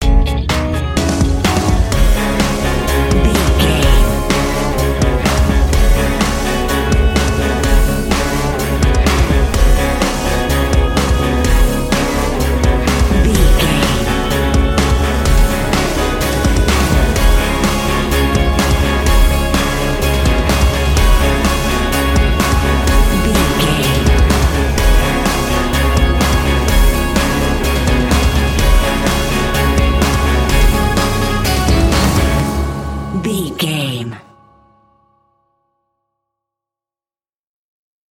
In-crescendo
Phrygian
C#
drums
electric guitar
bass guitar
Sports Rock
hard rock
aggressive
energetic
intense
nu metal
alternative metal